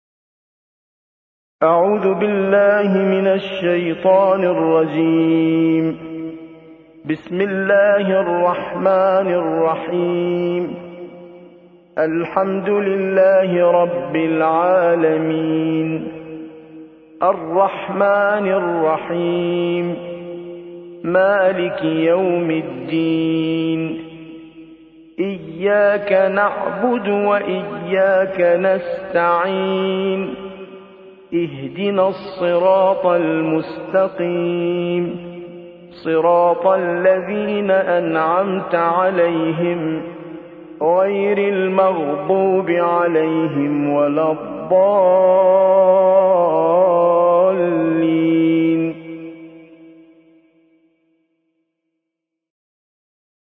سورة الفاتحة / القارئ